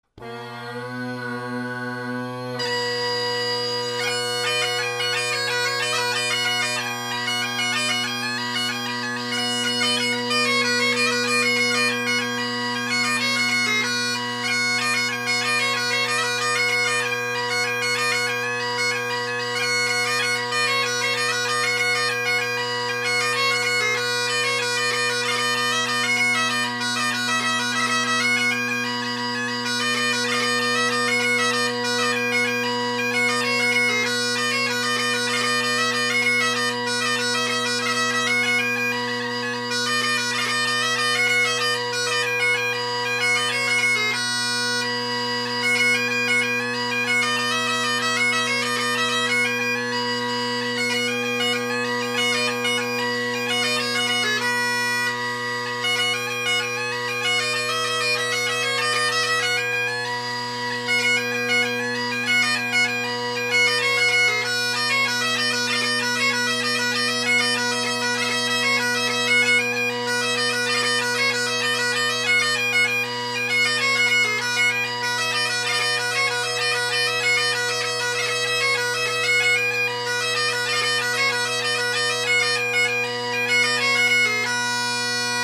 Great Highland Bagpipe Solo, Reviews
sight read, blackwood solo chanter